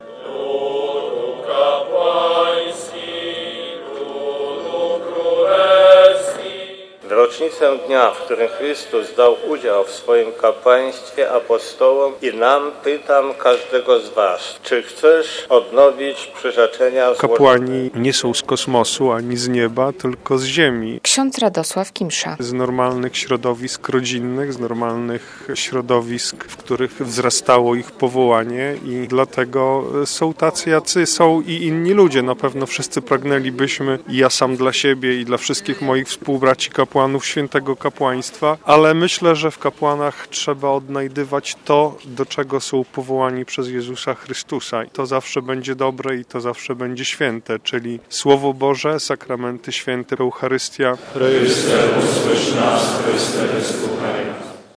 Wielki Czwartek w Kościele Katolickim - relacja
Kilkuset księży z całej archidiecezji modliło się rano w białostockiej katedrze na specjalnej mszy świętej krzyżma.